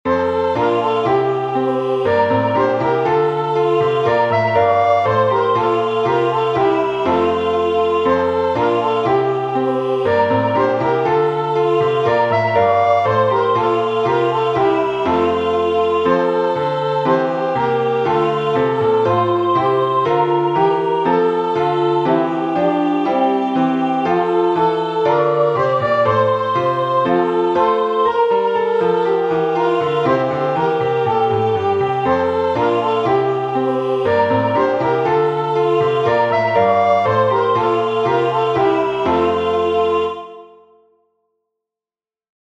Acclamation (CAN)My sheep hear my voice I
no4-alleluia-can-my-sheep-hear.mp3